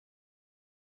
silent.mp3